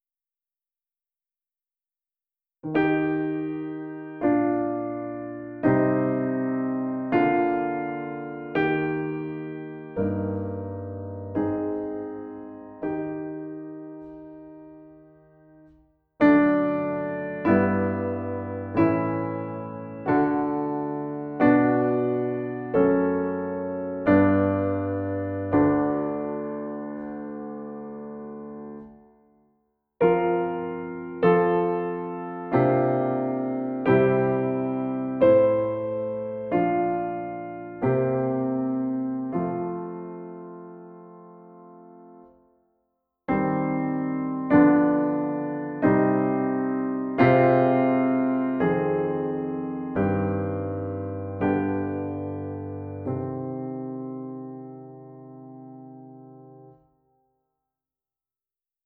Piano audio demo of Simplified Anglican Chants used with New Coverdale PsalterDownload
the-four-simplified-anglican-chants-audio-demo-on-piano.wav